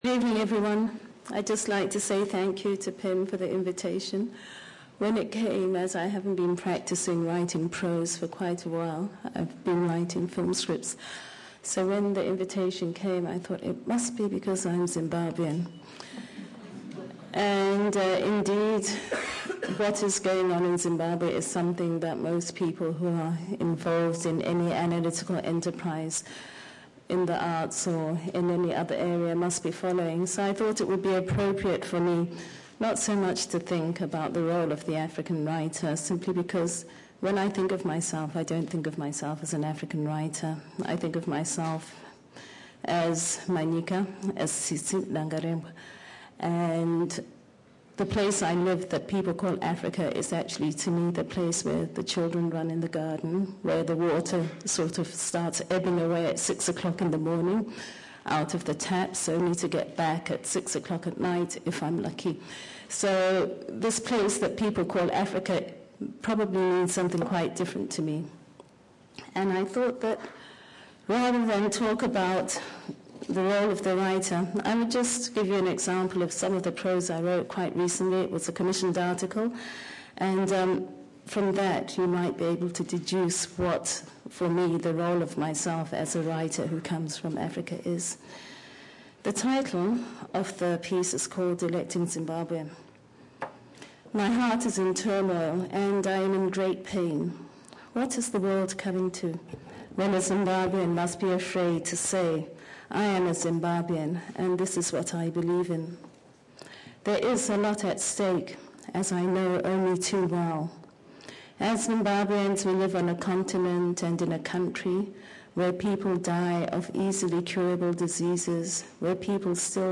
Tsitsi Dangarembga speaks about the role of the writer at the PEN American Centre